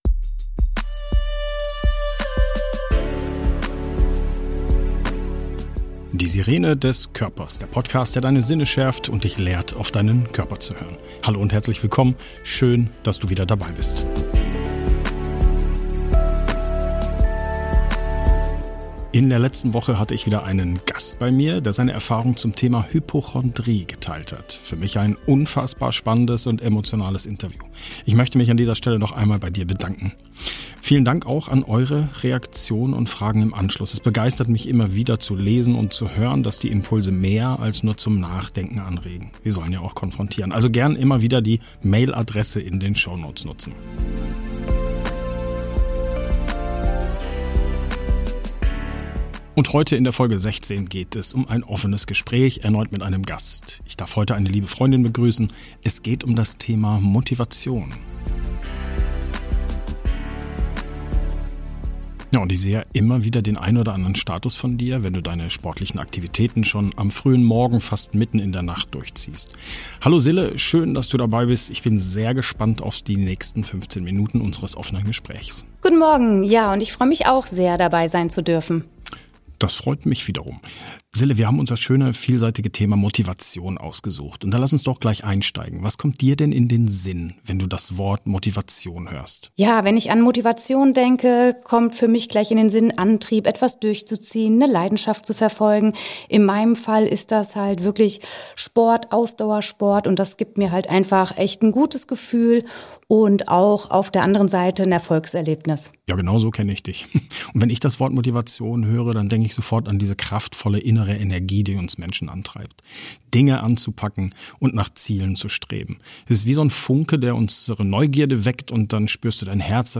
In dieser Podcast-Episode habe ich das Vergnügen, ein offenes Gespräch mit meinem Gast zu führen.